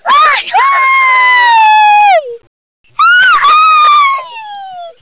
Wer nochmal mitraten möchte, kann sich ja zuerst die Tierstimmen anhören.